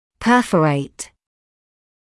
[‘pɜːfəreɪt][‘пёːфэрэйт]перфорировать, прободать